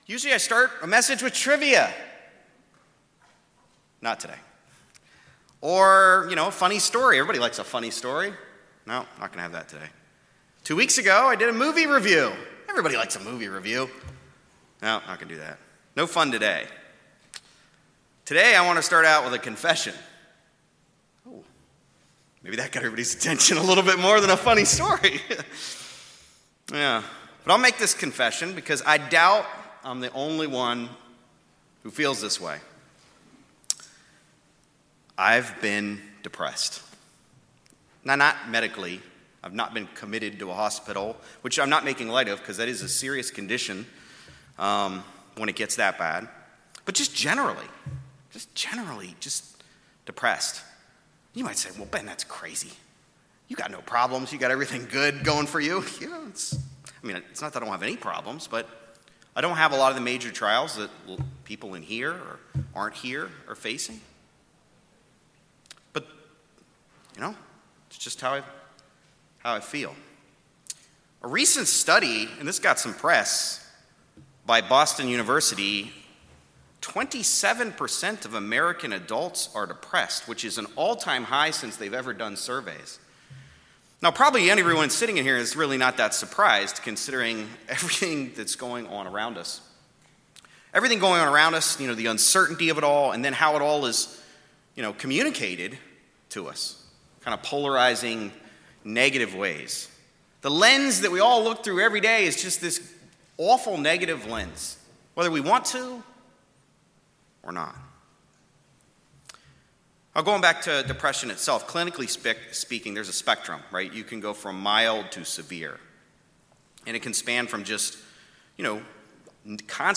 Sermons
Given in Greensboro, NC Jacksonville, NC Raleigh, NC